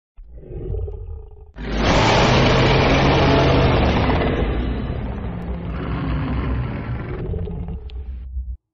Dragon Roar Sound Effect Free Download
Dragon Roar